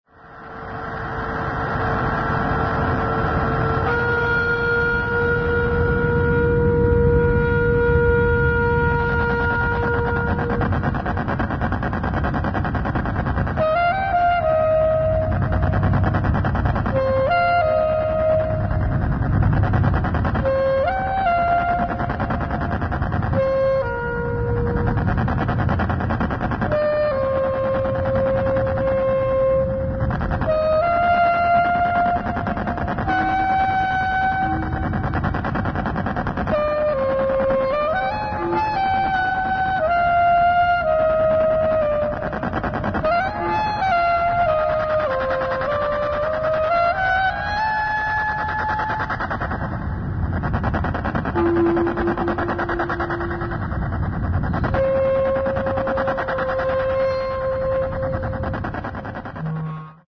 春の夜風に吹かれ、橋のオレンジの外灯に照されながら、私たちは演奏した。